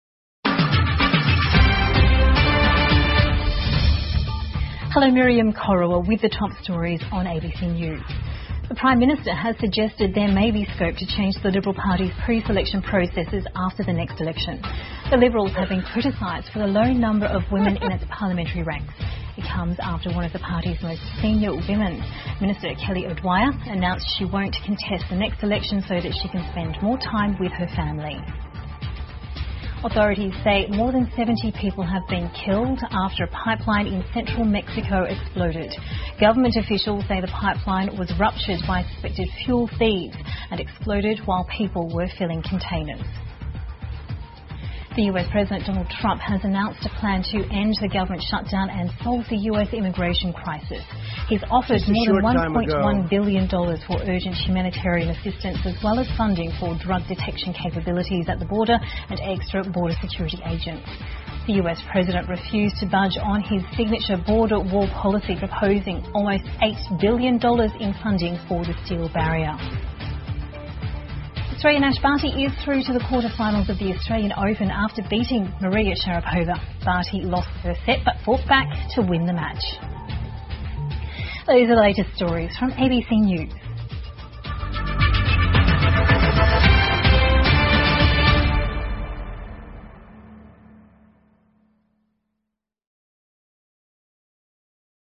澳洲新闻 (ABC新闻快递) 墨西哥输油管道爆炸致70余人死亡 特朗普提政府"开门"方案 听力文件下载—在线英语听力室